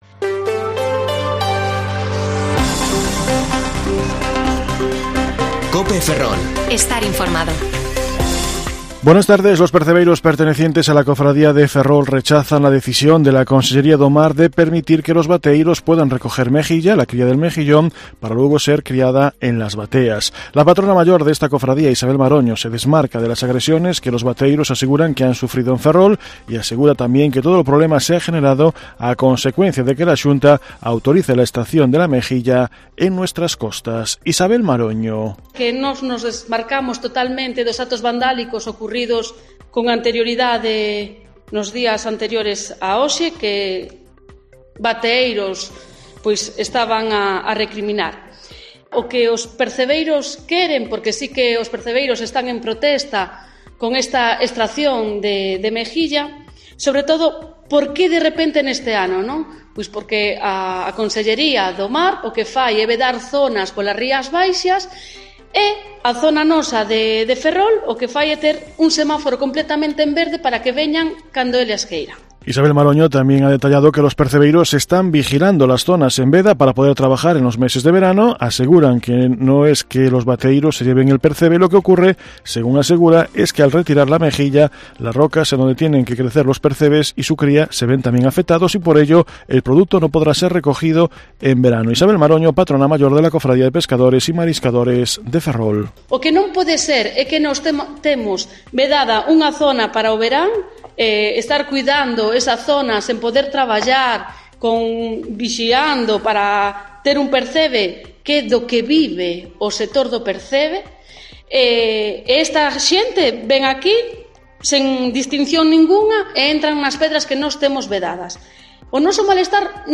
Informativo Mediodía COPE Ferrol 20/5/2022 (De 14,20 a 14,30 horas)